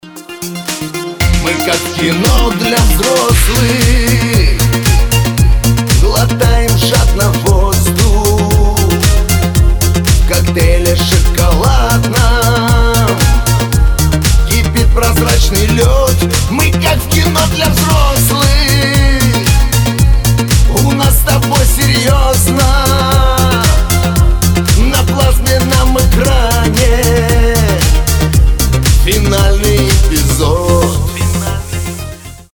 Танцевальные рингтоны, Русские рингтоны, Шансон рингтоны